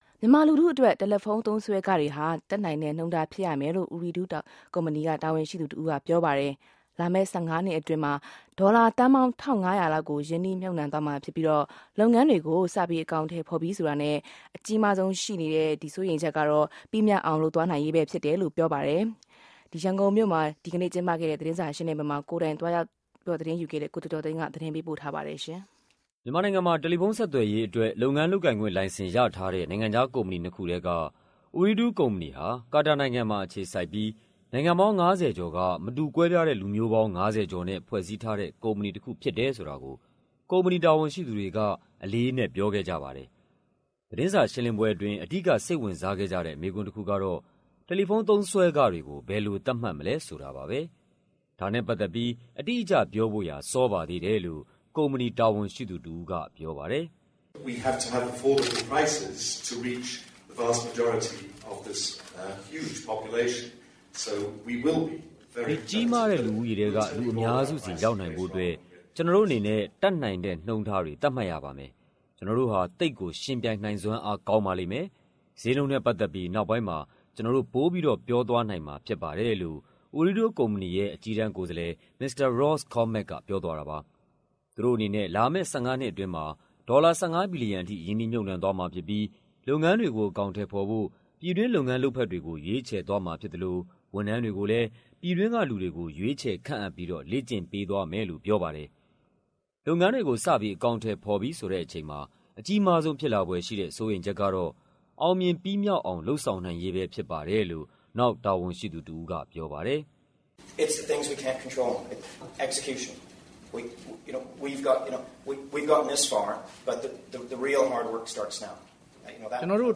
Ooredoo သတင်းစာရှင်းလင်းပွဲ